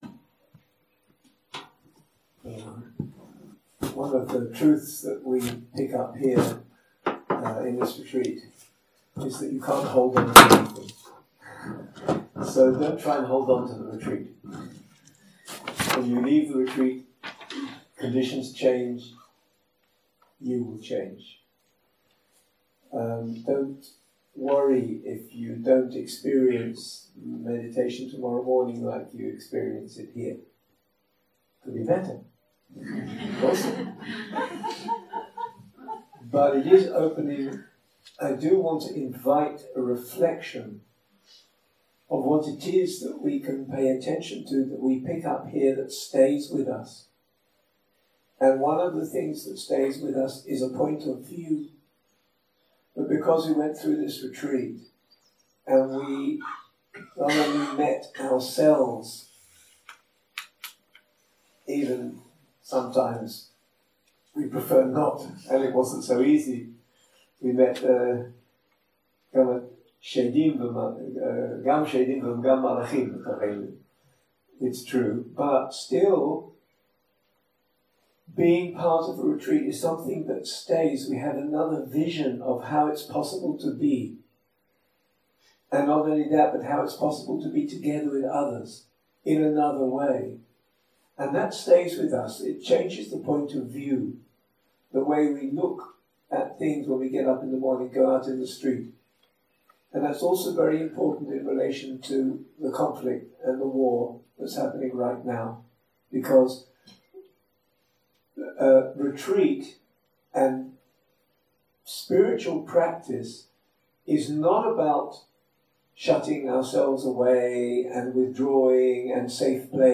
סוג ההקלטה: שיחות דהרמה
איכות ההקלטה: איכות נמוכה